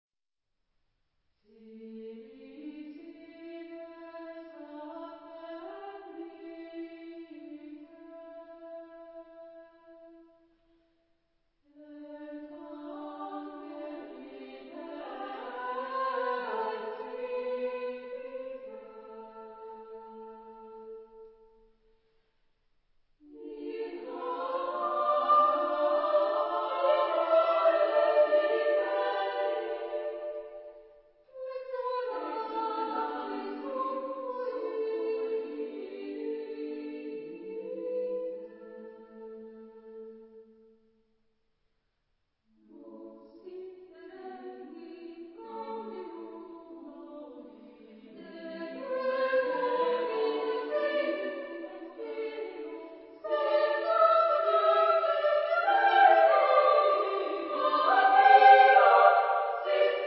Epoque: 20th century
Genre-Style-Form: Motet ; Hymn (sacred) ; Sacred
Type of Choir: SSA  (3 women voices )
Tonality: free tonality